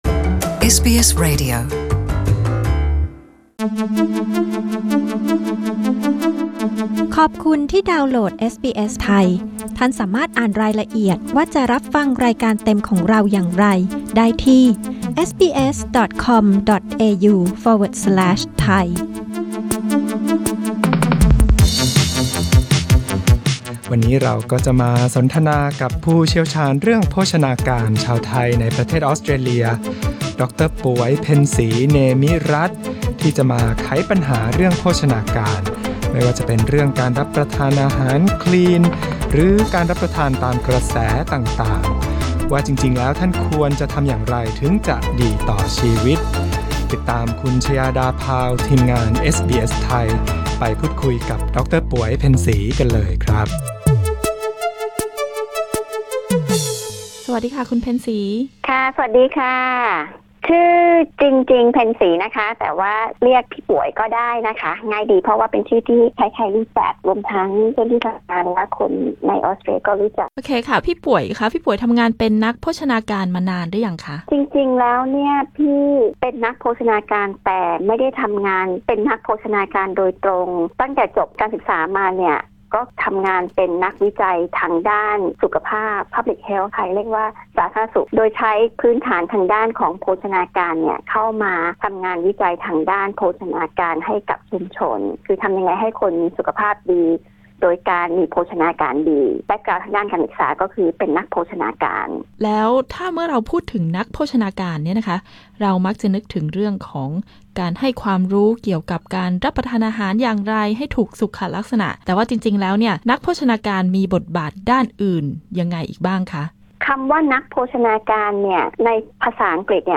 เอสบีเอส ไทย สนทนากับผู้เชี่ยวชาญด้านโภชนาการชาวไทยในประเทศออสเตรเลีย